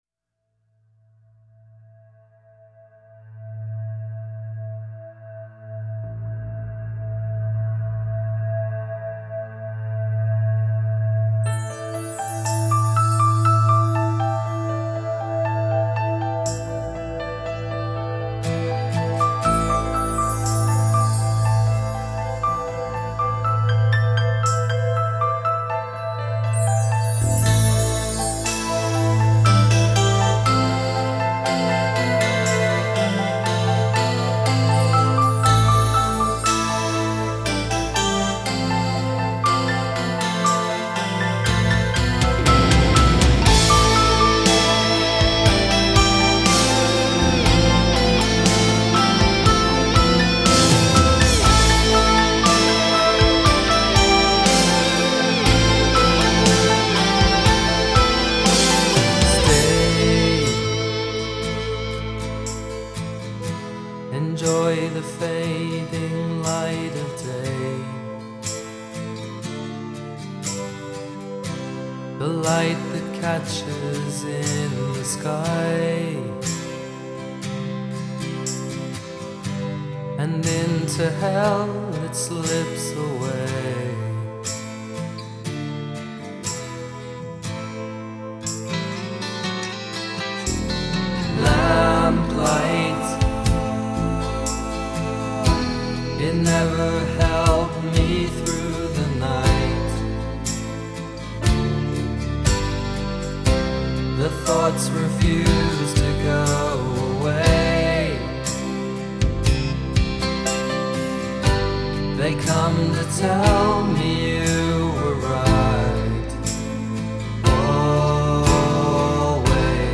интернациональная фолк-группа